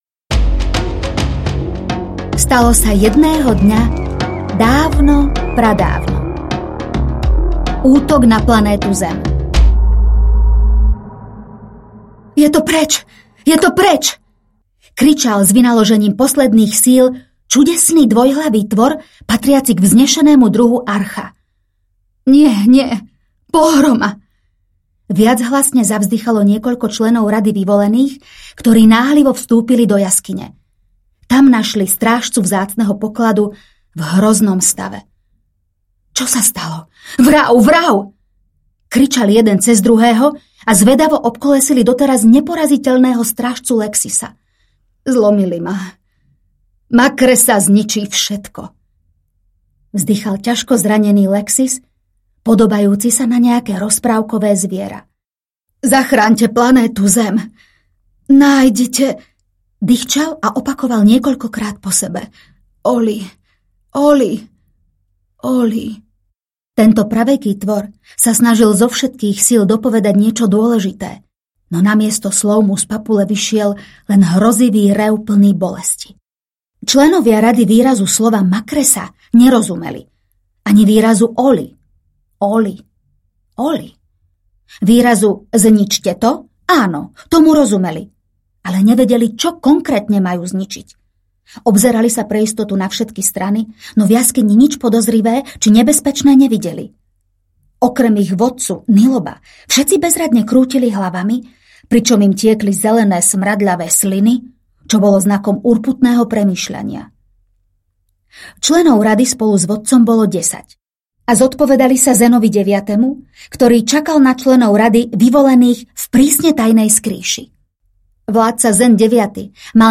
Ukázka z knihy
kuzelna-krajina-slimi-slami-audiokniha